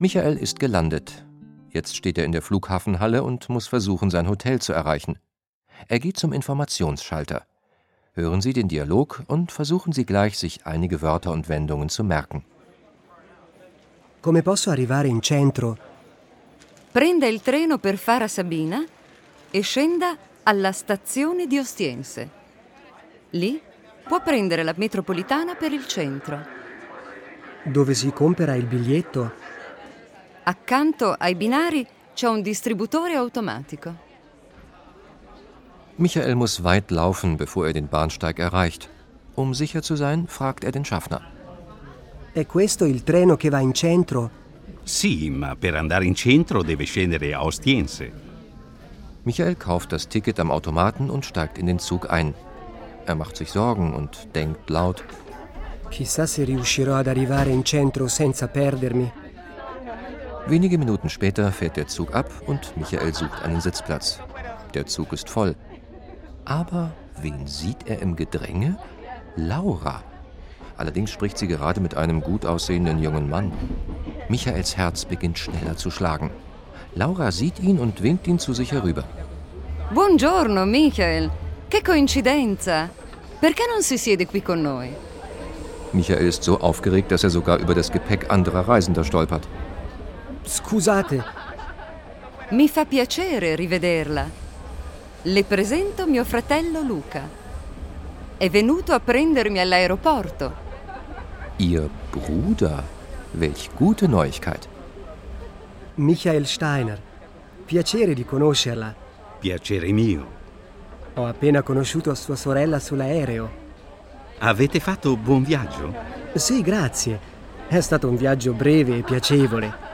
Der Sprachkurs zum Hören mit 4 Audio-CDs und Begleitheft
Dialoge mit Übersetzung